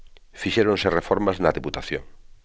fiSÉroNse rrefÓrmas na DeputaTjóN.